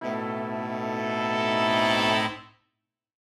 Index of /musicradar/gangster-sting-samples/Chord Hits/Horn Swells
GS_HornSwell-B7b2sus4.wav